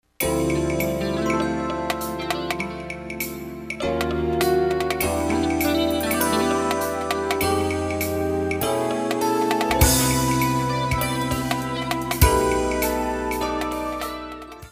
Jazz 98a